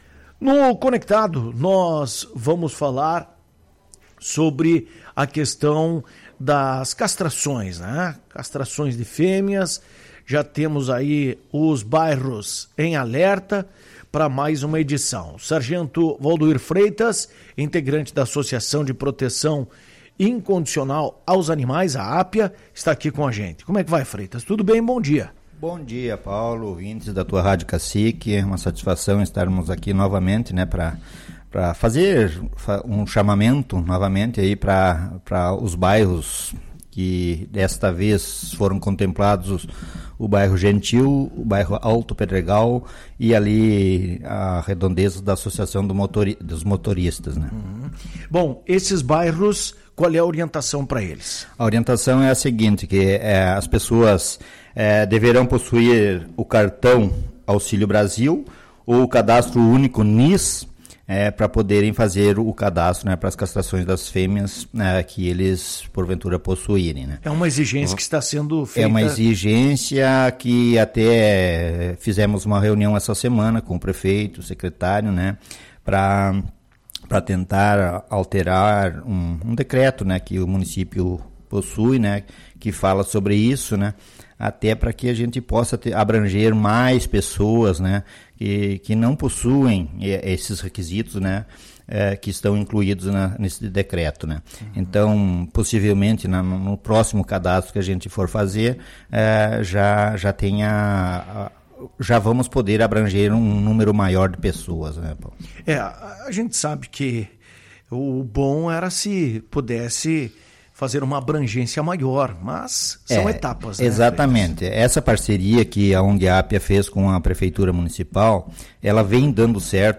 Em entrevista ao programa Conectado da Tua Rádio Cacique